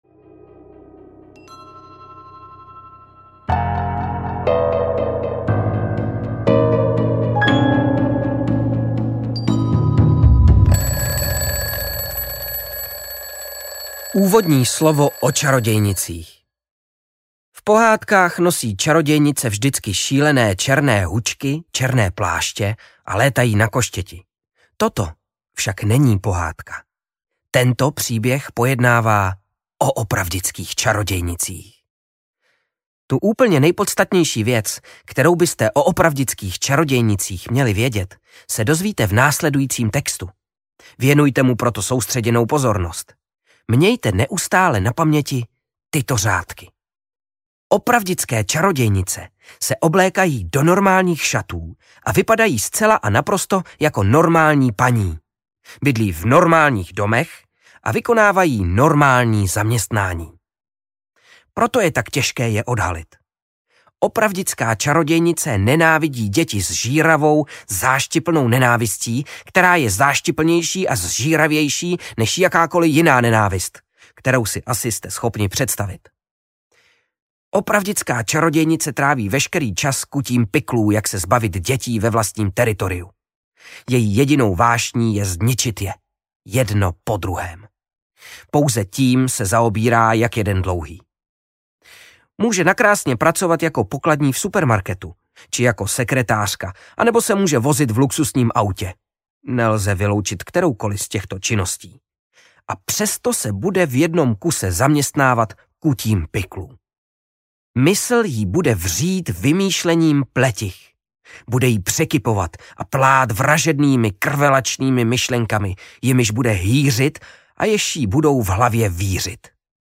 Čarodějnice audiokniha
Ukázka z knihy
• InterpretKryštof Hádek